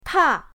ta4.mp3